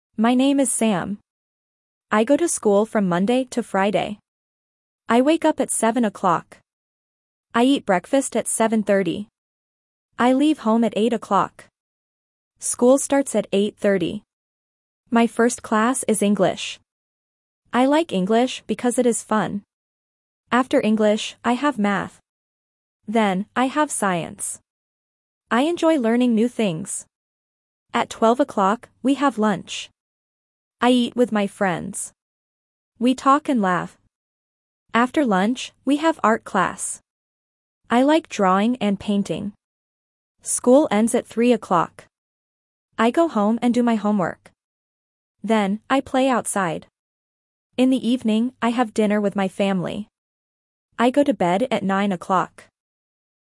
Reading A1 - A Day at School